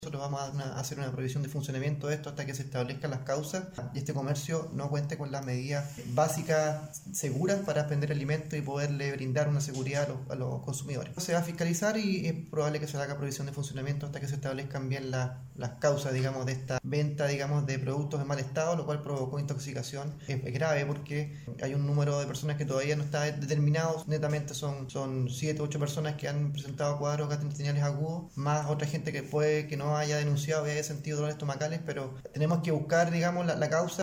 De acuerdo a la información que las autoridades de salud detallan, los casos se habrían producido durante el fin de semana recién pasado, teniendo como denominador común, el haber consumidos alimentos adquiridos en un local delivery ubicado en el centro de Osorno, dijo el jefe provincial de seremi de Salud Osorno, Felipe Vergara.